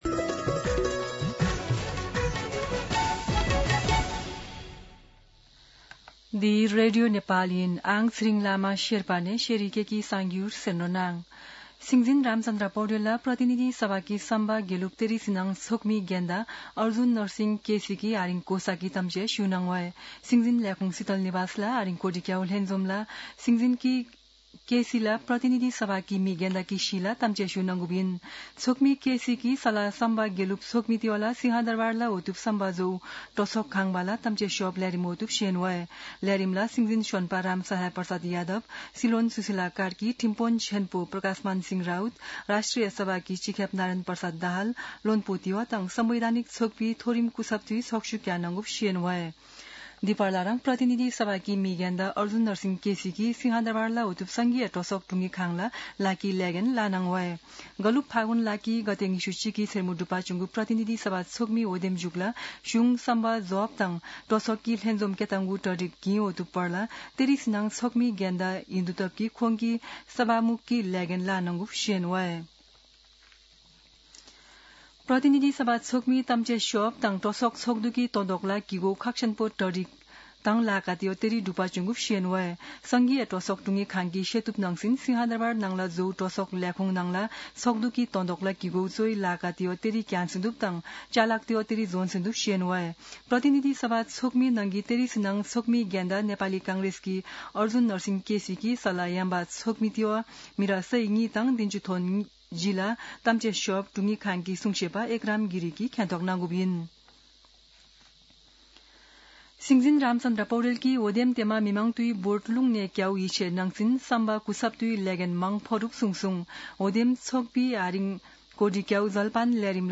शेर्पा भाषाको समाचार : ११ चैत , २०८२
Sherpa-News-11.mp3